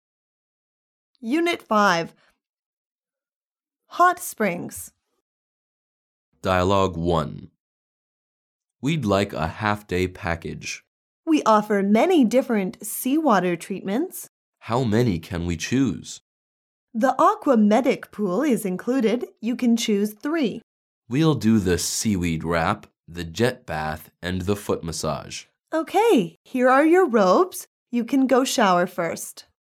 Dialouge 1